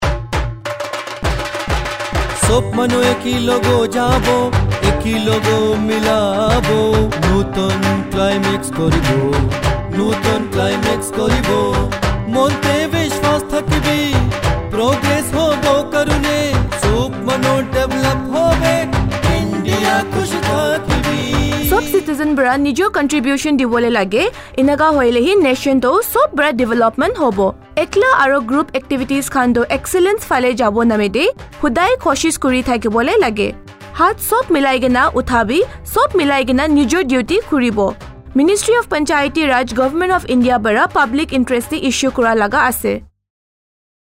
177 Fundamental Duty 10th Fundamental Duty Strive for excellence Radio Jingle Nagamese